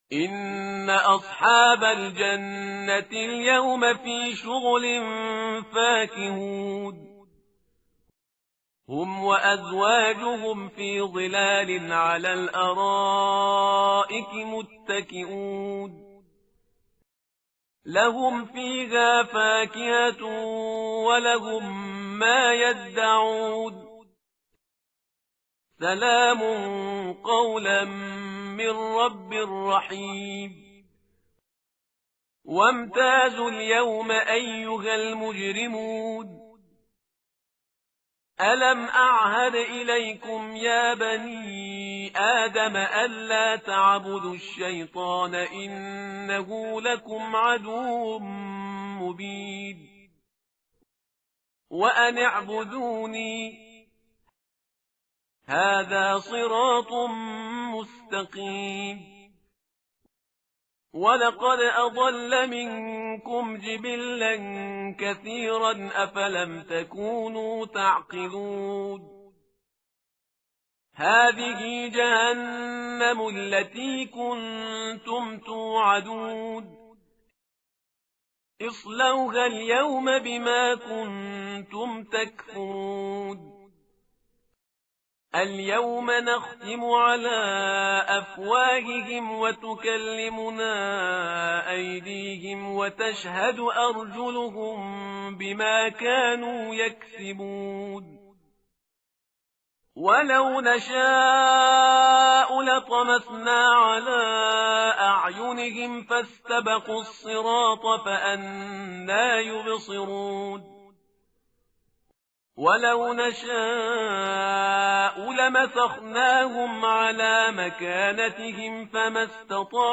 tartil_parhizgar_page_444.mp3